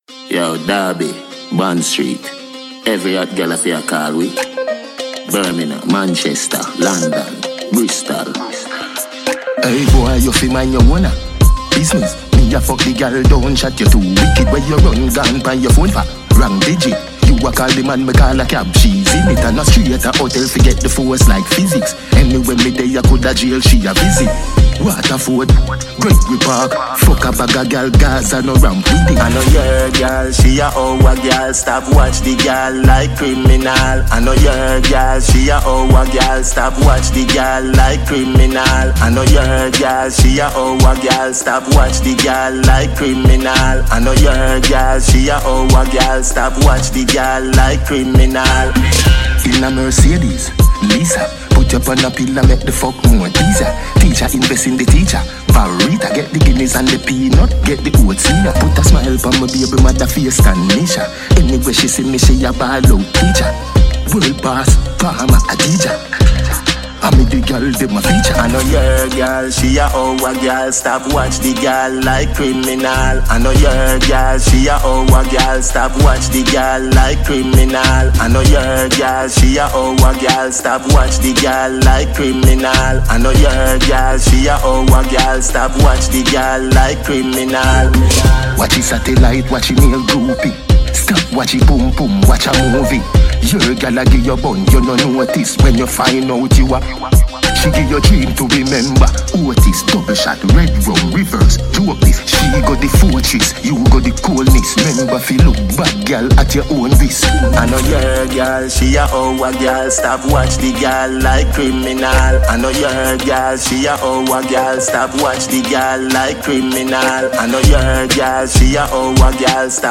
Top-notch Jamaican Dancehall superstar